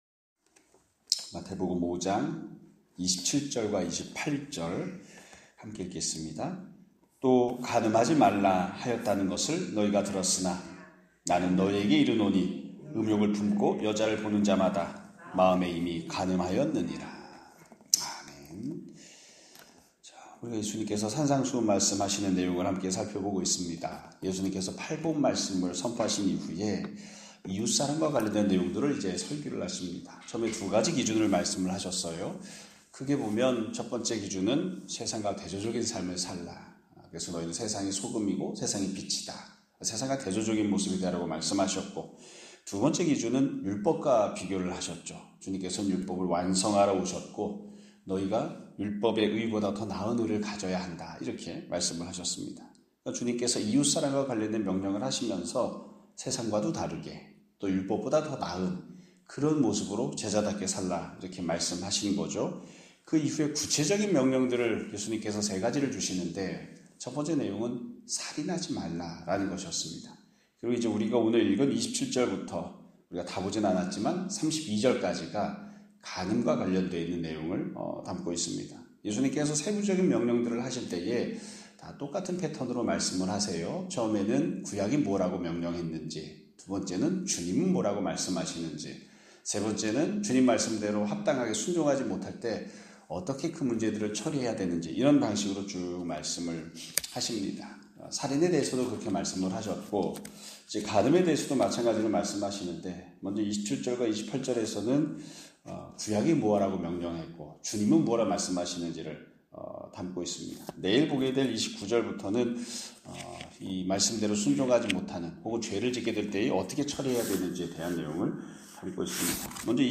2025년 5월 26일(월요일) <아침예배> 설교입니다.